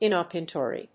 ìn・òp・por・túne・ly
音節in･op･por･tune･ly発音記号・読み方ɪnɑ̀pərt(j)úːnli|ɪnɔ́pətjùːn-